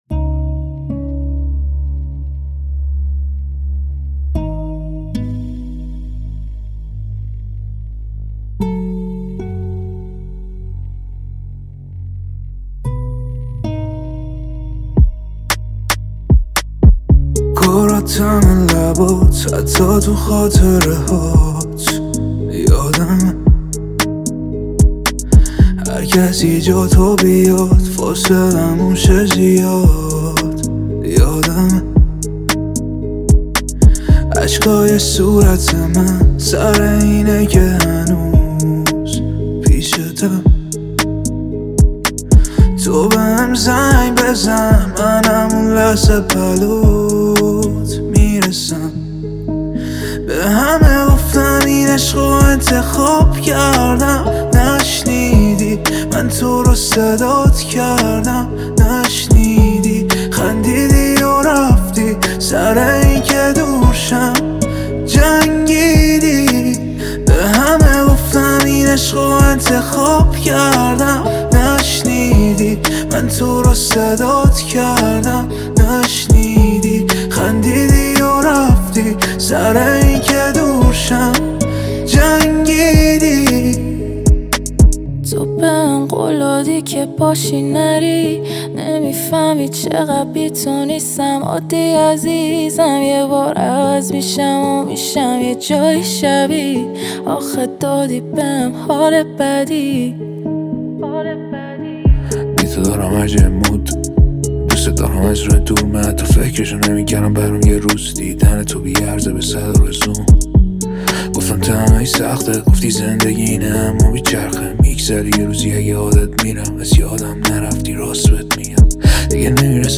اهنگ جدید رپ